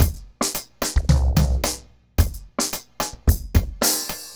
RemixedDrums_110BPM_08.wav